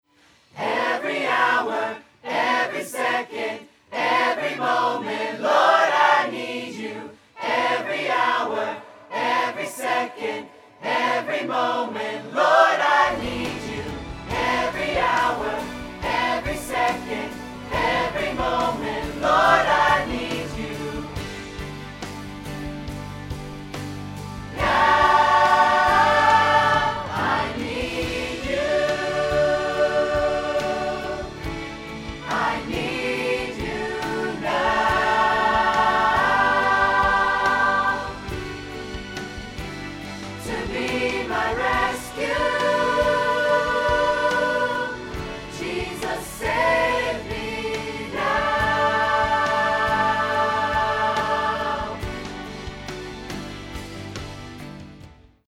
• 0:00 – 0:06 – Choir Mics Soloed
• 0:07 – 0:13 – Choir Mics with Reverb
• 0:13 – 0:55 – Choir Mics with Backing Track
Audio-Technica PRO 45